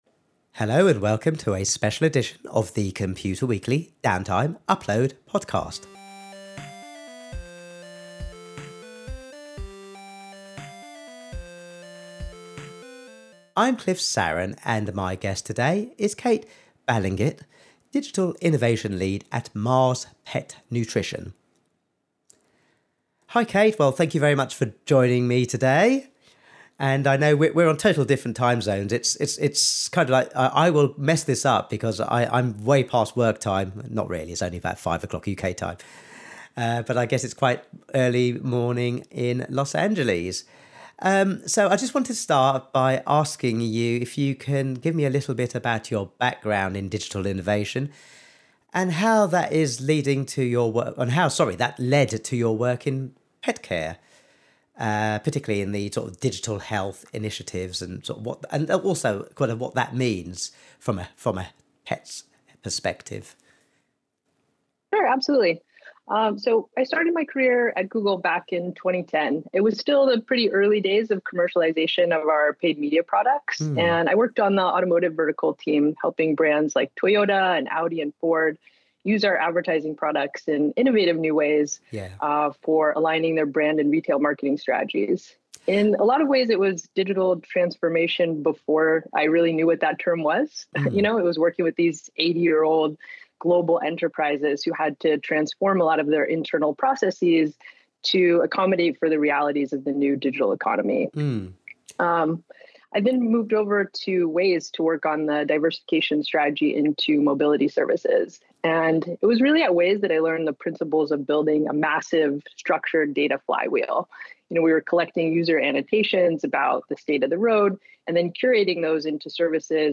Interview: Differentiating with AI in pet care | Computer Weekly